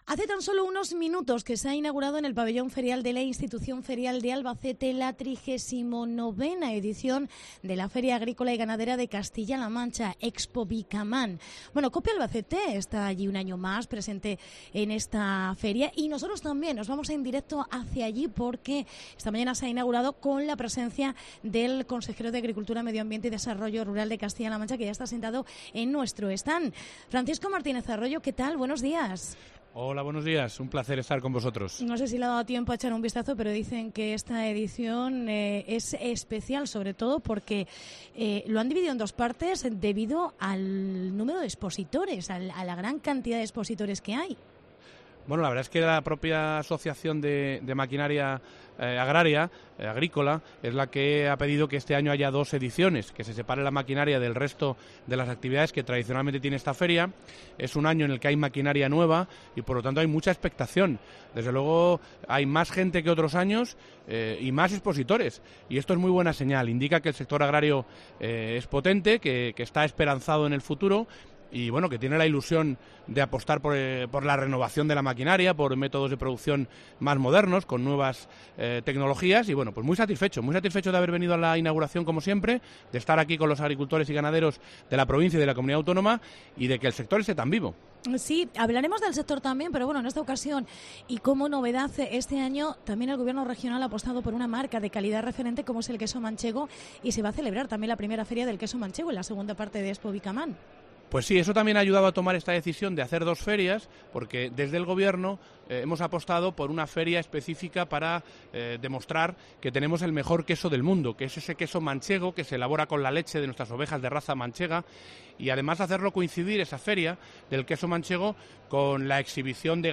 Se inaugura Expovicaman 2019 en Albacete. Entrevista con el Consejero Francisco Martínez Arroyo